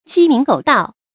注音：ㄐㄧ ㄇㄧㄥˊ ㄍㄡˇ ㄉㄠˋ
雞鳴狗盜的讀法